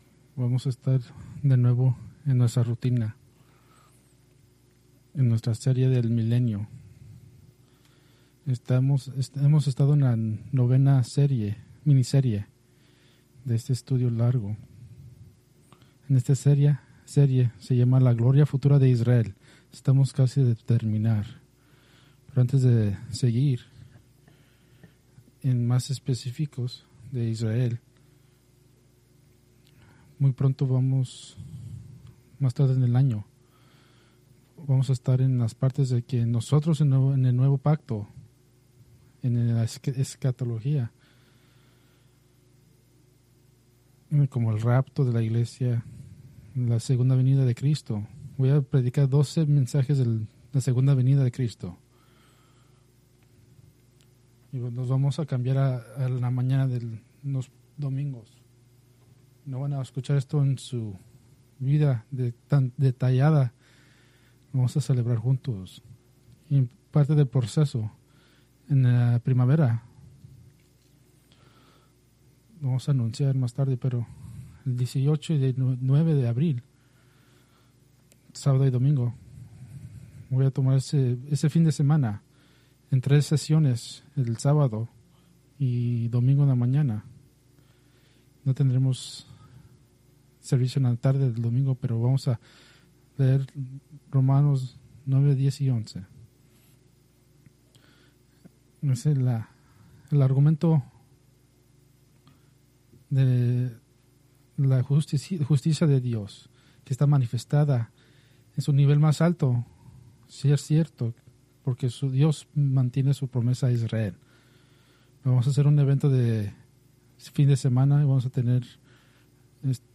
Preached January 25, 2026 from Escrituras seleccionadas